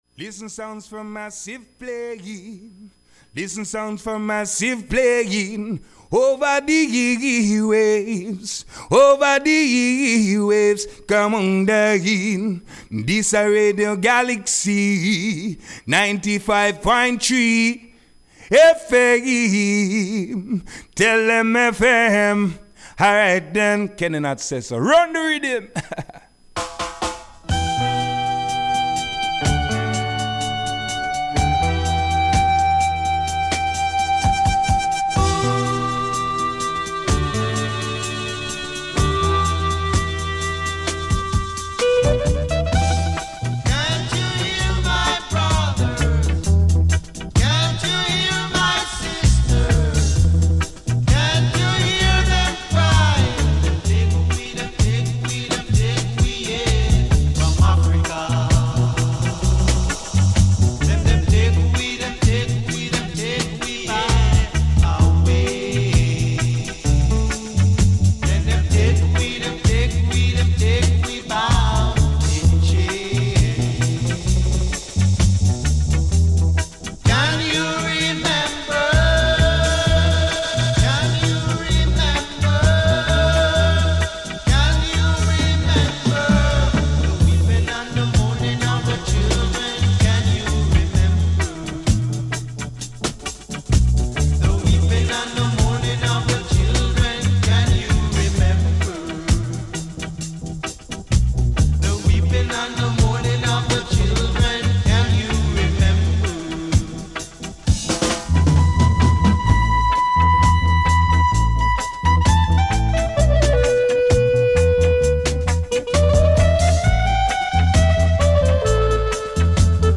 Sound 4 Massive - le REPLAY Retrouvez votre rendez-vous reggaephonique SOUND 4 MASSIVE, animé par I Bingi Sound et enregistré lundi 8 septembre dans les studios de Galaxie Radio Ecoutez, profitez et partagez